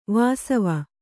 ♪ vāsava